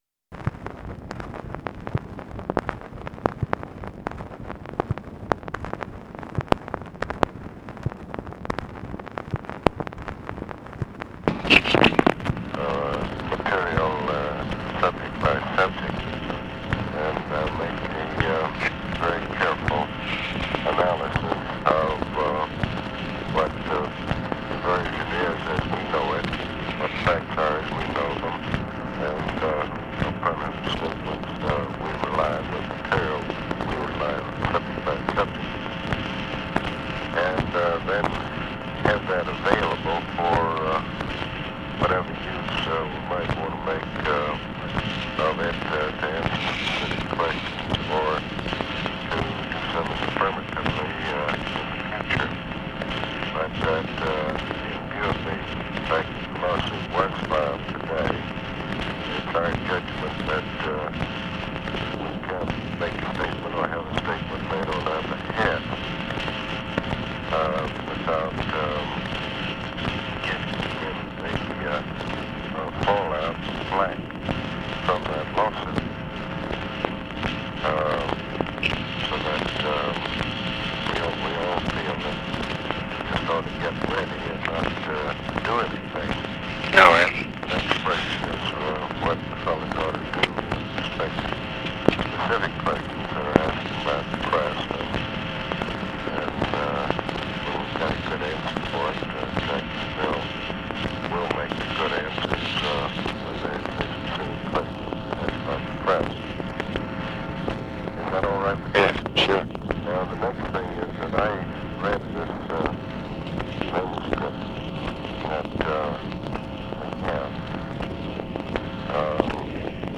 Conversation with ABE FORTAS and OFFICE CONVERSATION, December 17, 1966
Secret White House Tapes